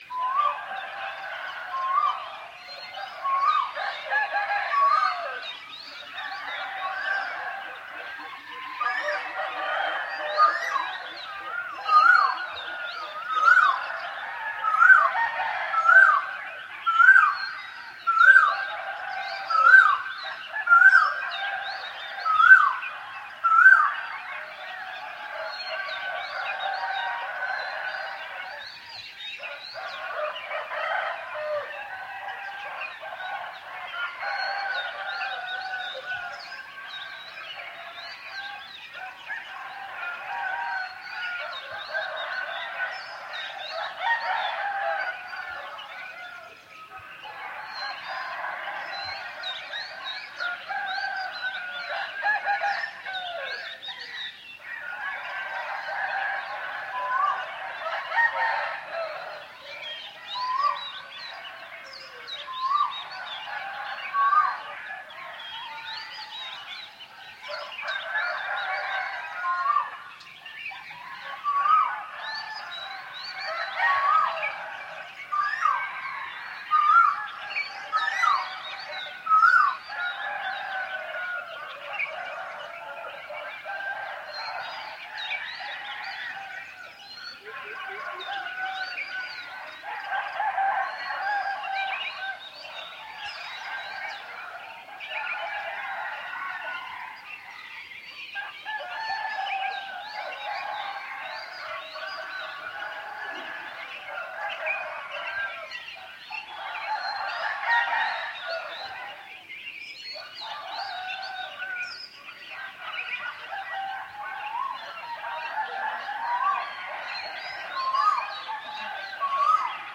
Рассвет на ферме в азиатской деревне